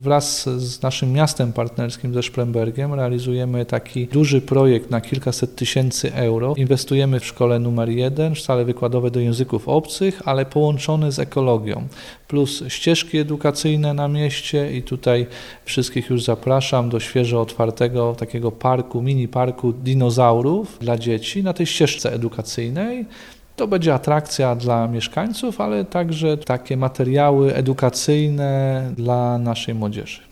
– Korzyści z realizacji tego projektu będą mieli nie tylko uczniowie, ale także inni mieszkańcy – powiedział burmistrz Mirosław Gąsik: